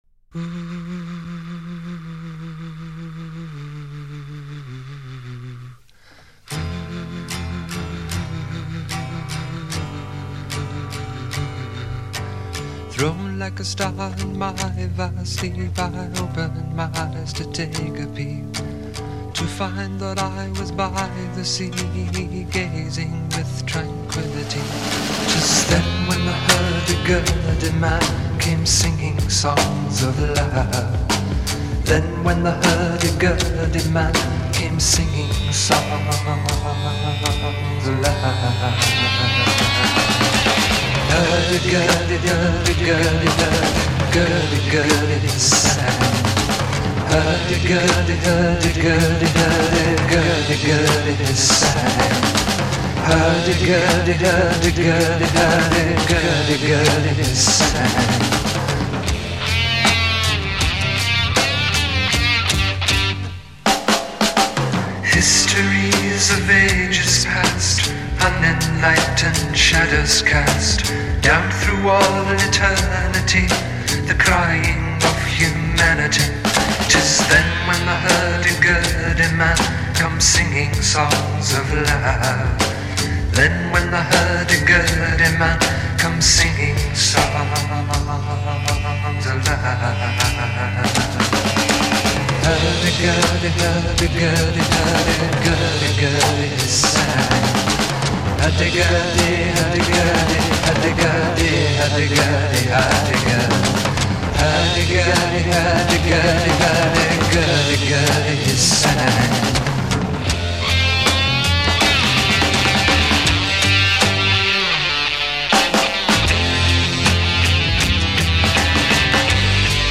voice, guitar, and tambura
Intro   Humming, add guitar
Verse   Voice modulated with tremolo unit. a
Refrain 2   Double-tracked vocal c
Refrain 2   Double-tracked with less reverberation. c
Verse   Guitar solo with fuzz tone.
Refrain 2   Voice at octave, repeat and fade. e